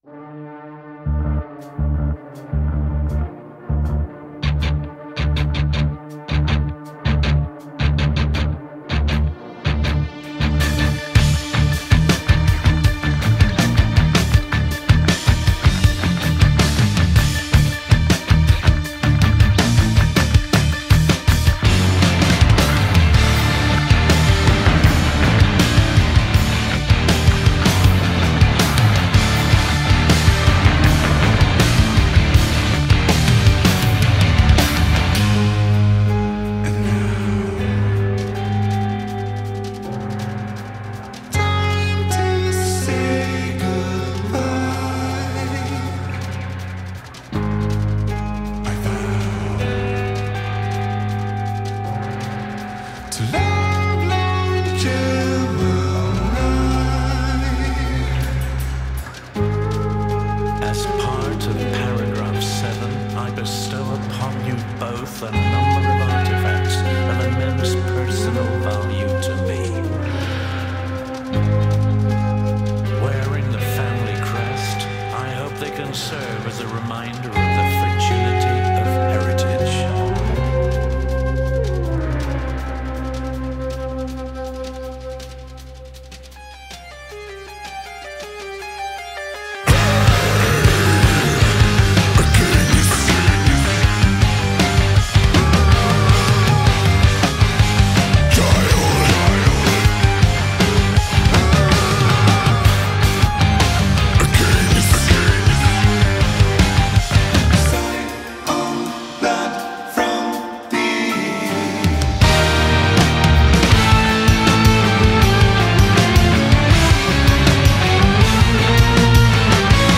Progressive Metal, Progressive Rock